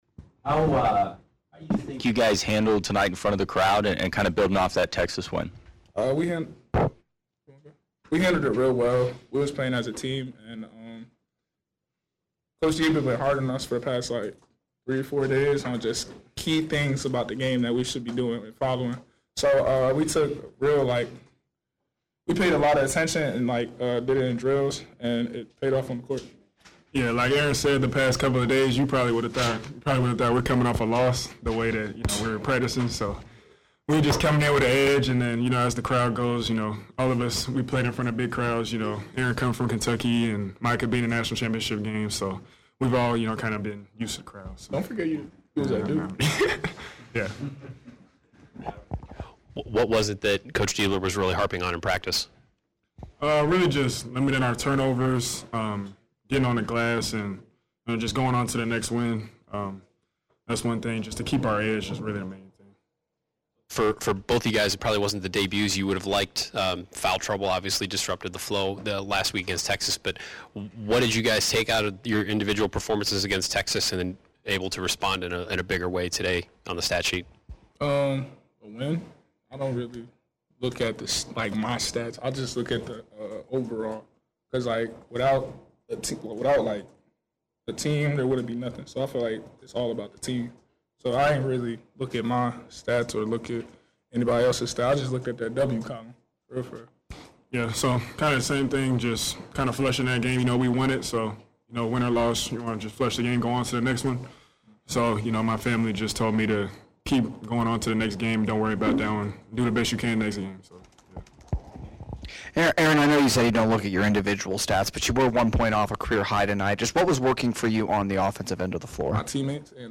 Postgame Press Conference